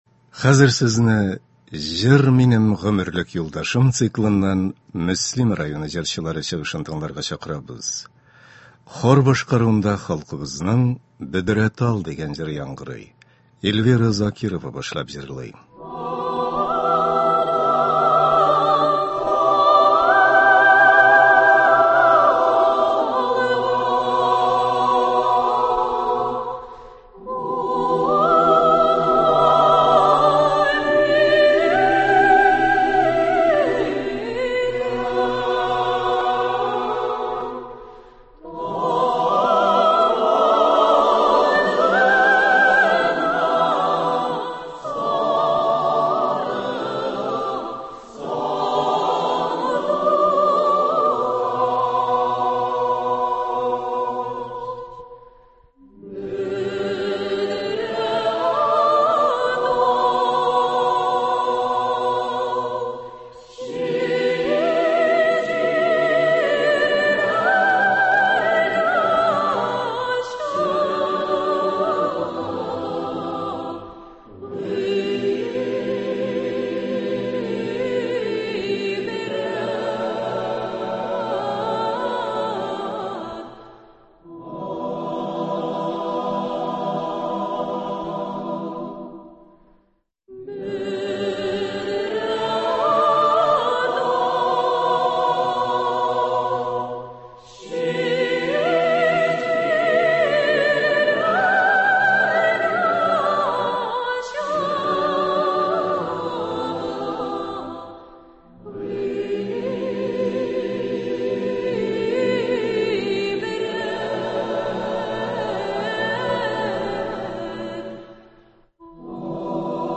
Концерт (22.11.21)